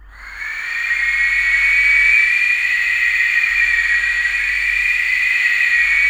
TURBINES.wav